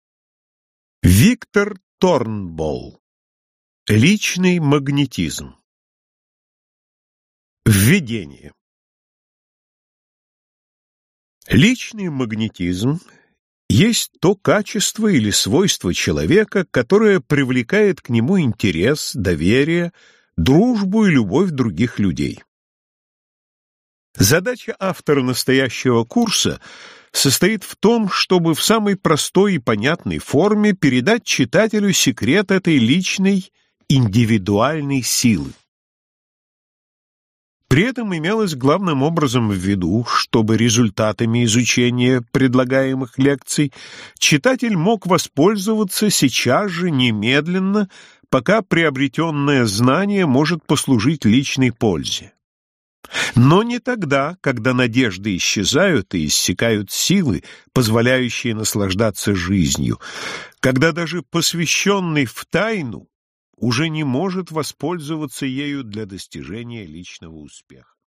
Аудиокнига Личный магнетизм. 15 уроков для достижения успеха | Библиотека аудиокниг